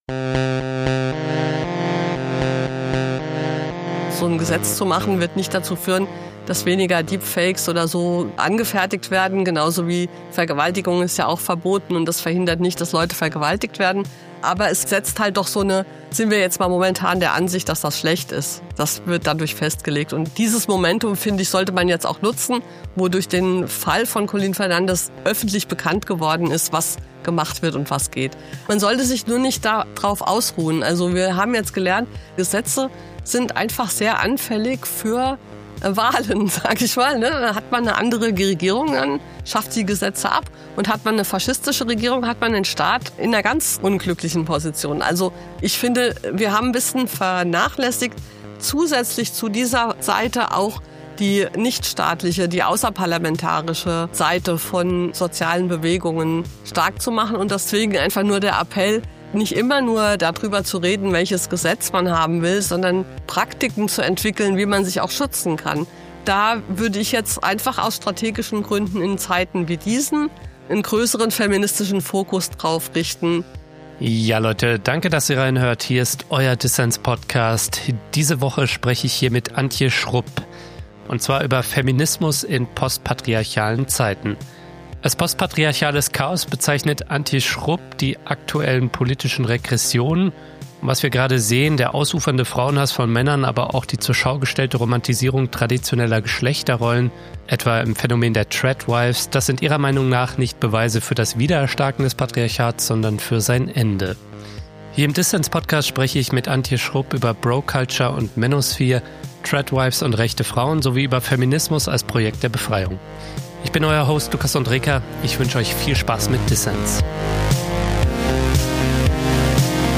Ein Gespräch über die Austrocknung der Manosphere, Tradwives als Antwort auf die Vereinbarkeitslüge und Feminismus als Projekt der Befreiung.